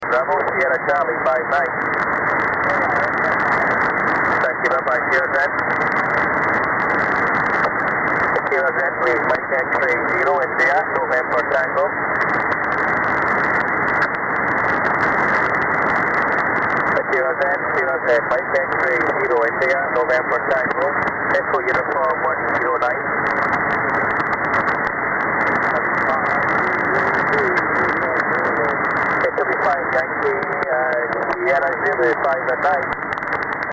MX0INT EU-109 20m SSB 10/05/2014